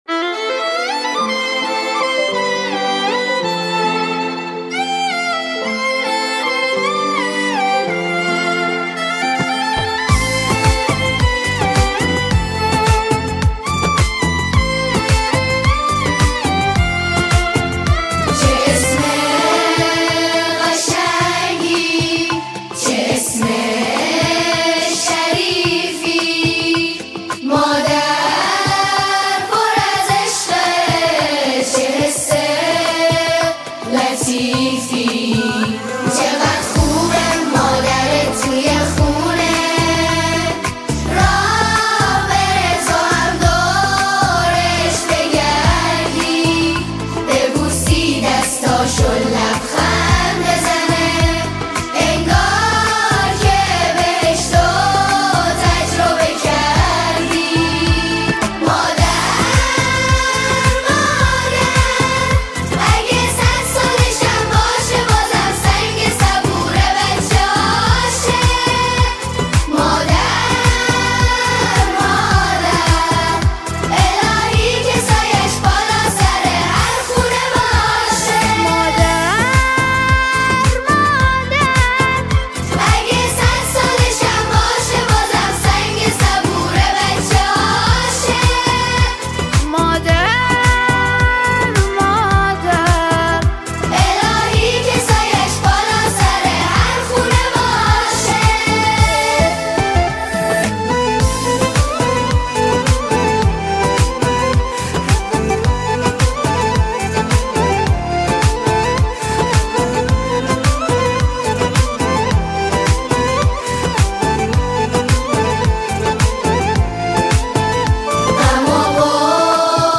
نماهنگ زیبا و احساسی